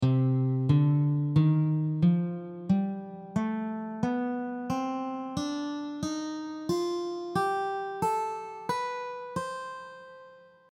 The examples will help you to hear the notes of each scale.
C Melodic minor scale
Melodic-minor-scale-audio.mp3